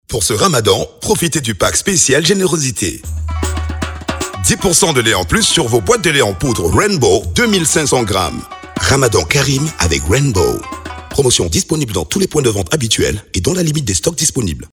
spot-radio-promo-djibouti-1_157_5.mp3